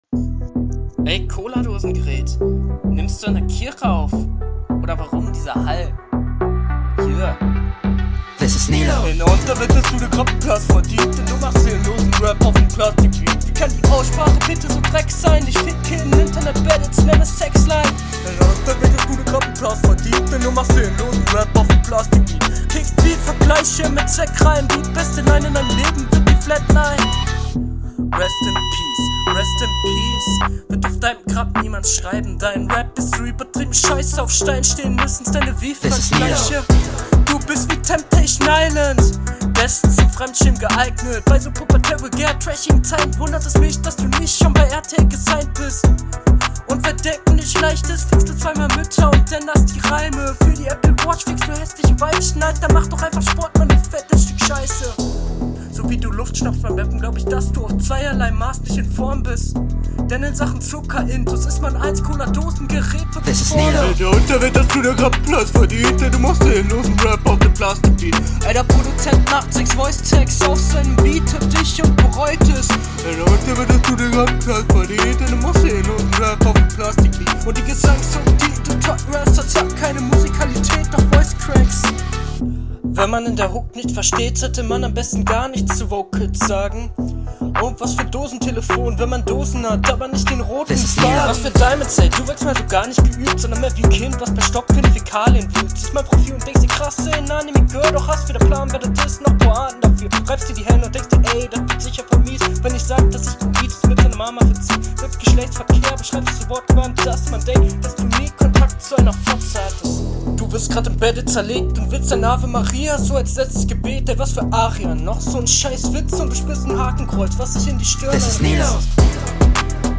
Die Stimme ist viel zu leise und dünn gemischt.